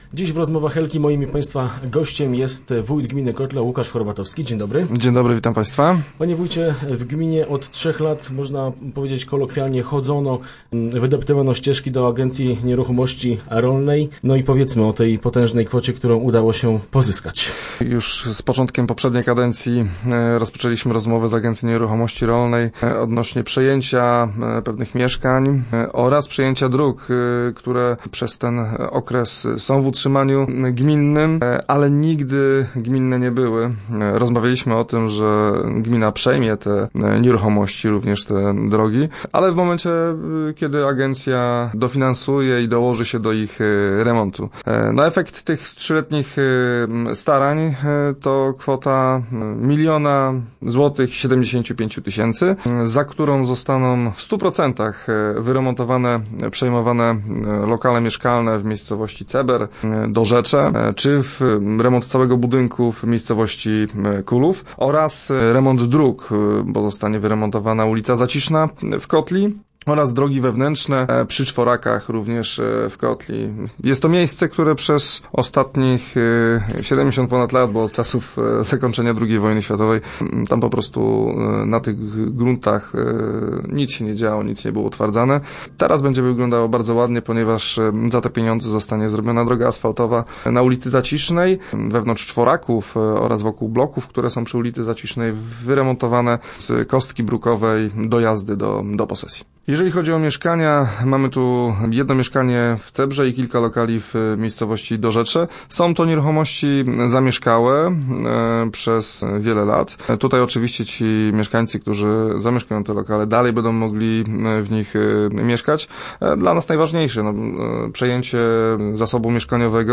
Odpowiedzi na to pytanie udzielił wójt Łukasz Horbatowski we wtorkowych Rozmowach Elki.